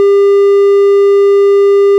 triangle wave:
sound-triangle.wav